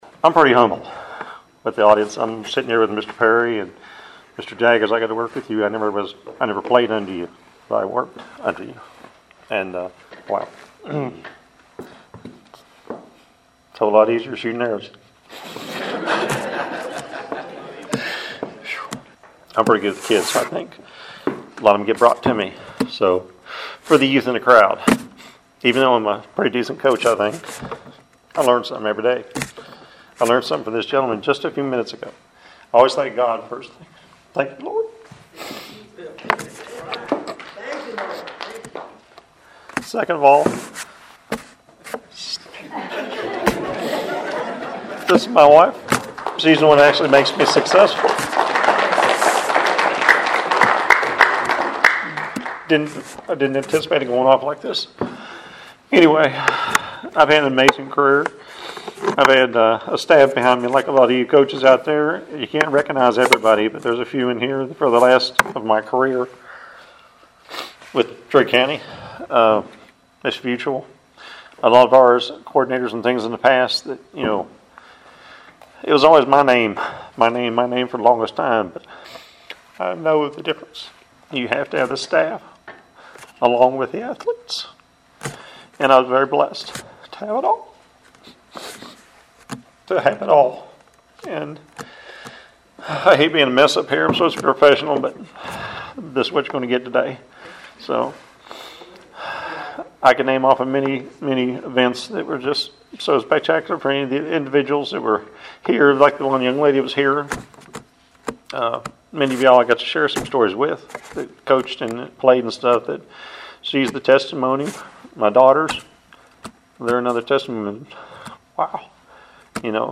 acceptance speech